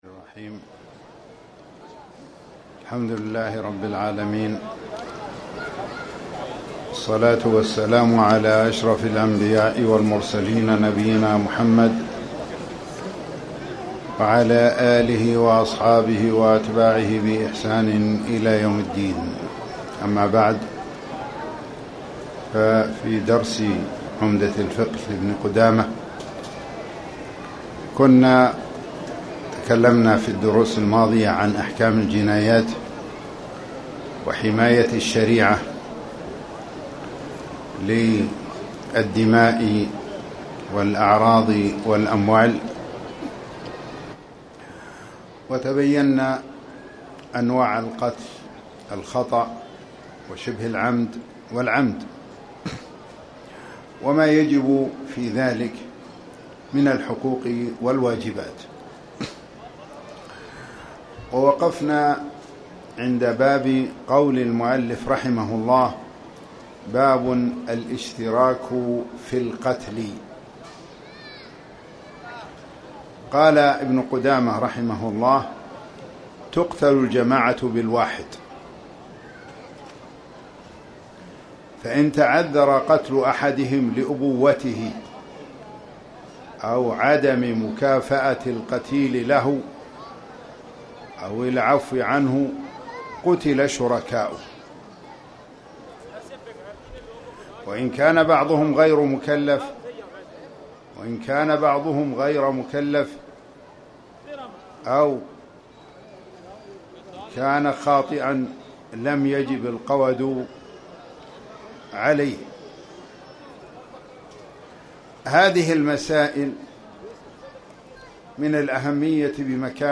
تاريخ النشر ٢٥ شوال ١٤٣٨ هـ المكان: المسجد الحرام الشيخ